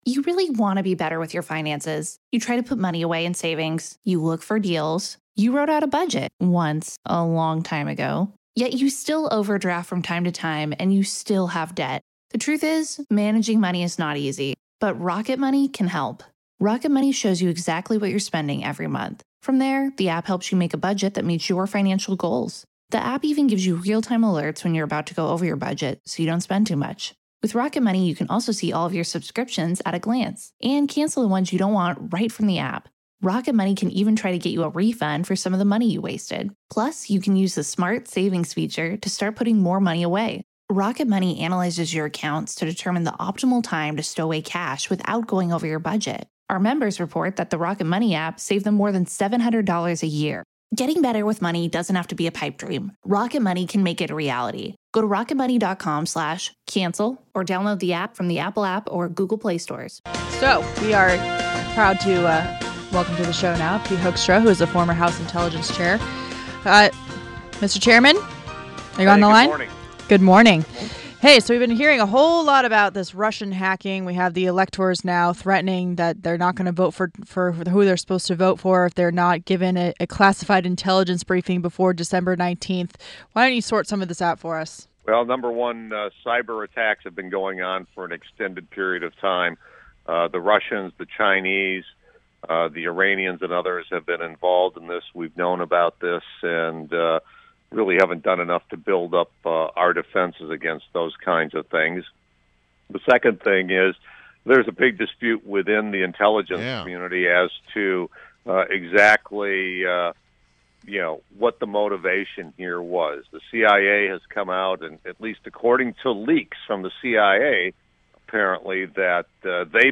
WMAL Interview - PETE HOEKSTRA - 12.14.16
INTERVIEW — PETE HOEKSTRA – is the former Chairman of the U.S. House Intelligence Committee